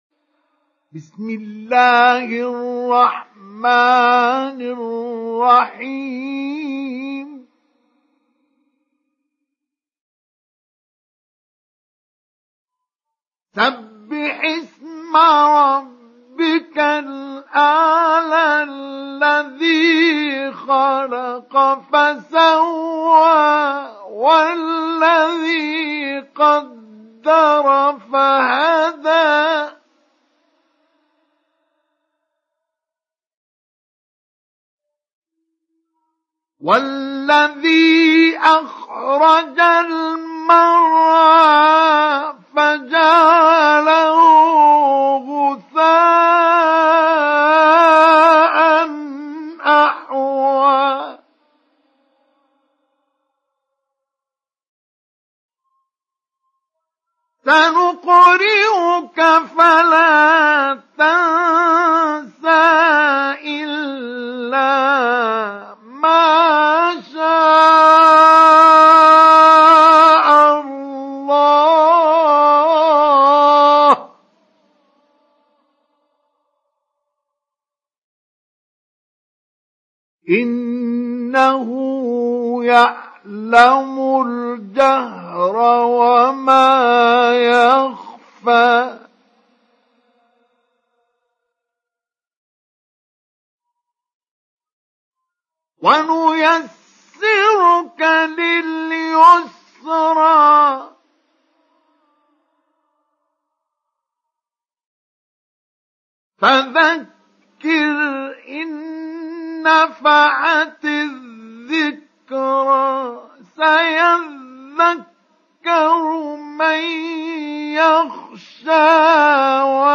Surah Al Ala Download mp3 Mustafa Ismail Mujawwad Riwayat Hafs from Asim, Download Quran and listen mp3 full direct links